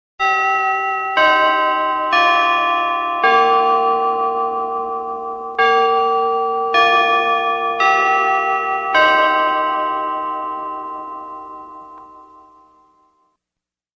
Chimes - Westminster.mp3